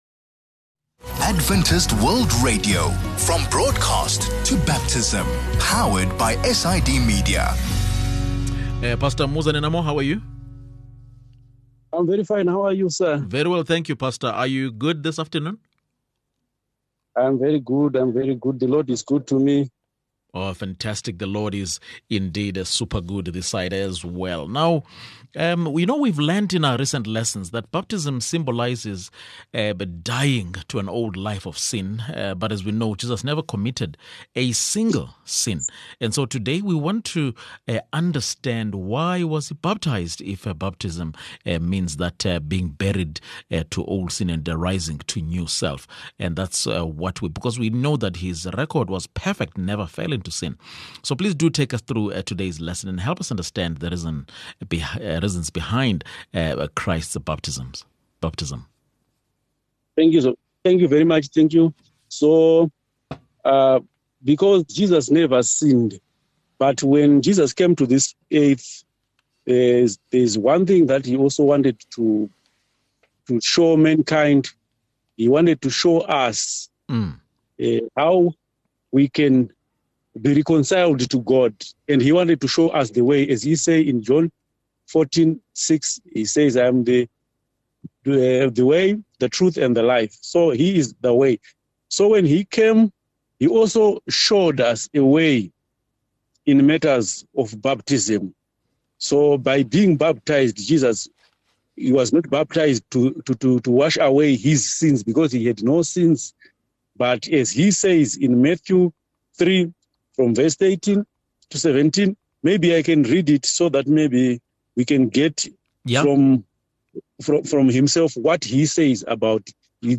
21 Oct VOP Lesson | Why Was Jesus Baptised?